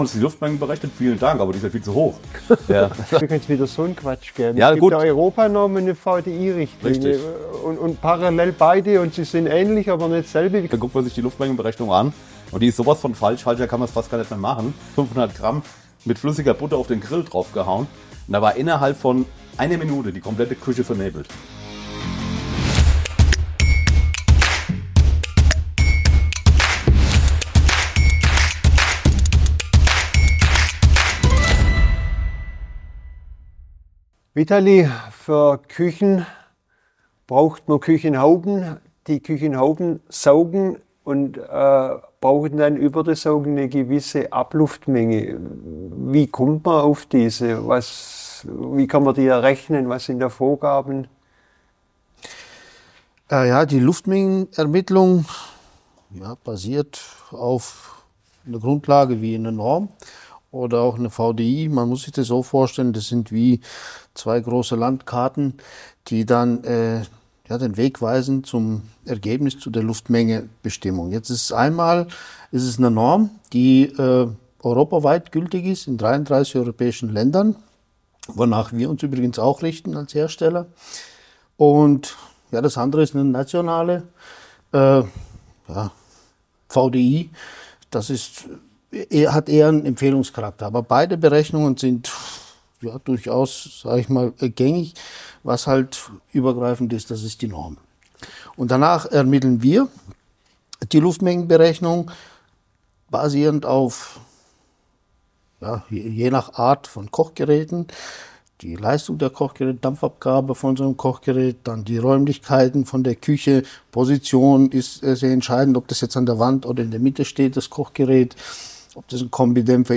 Episode 24 Die richtige Menge an Abluft für eine Küche - Expertentalk ~ Luftpost – Der Podcast für industrielle Luftreinigung und Lüftungstechnik Podcast